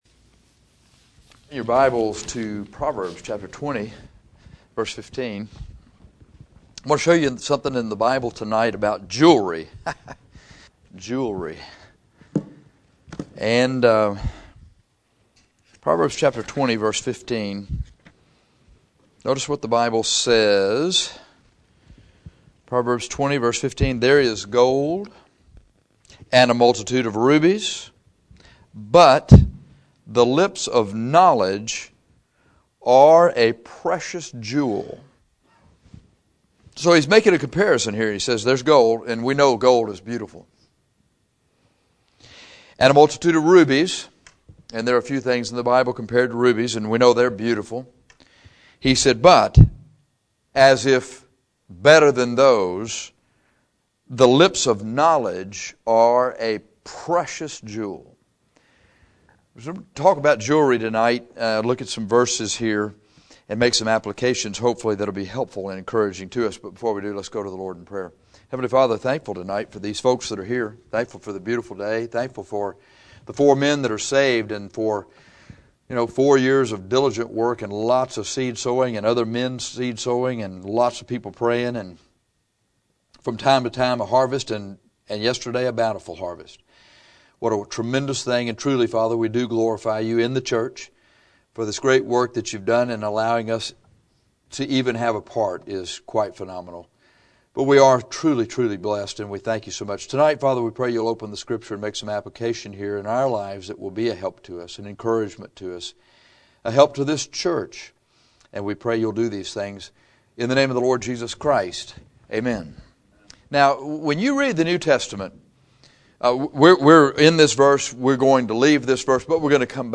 This sermon examines godly jewelry in the Bible contrasted to cosmetic jewelry. Cosmetic jewelry draws attention to the wearer; godly jewelry to the Lord.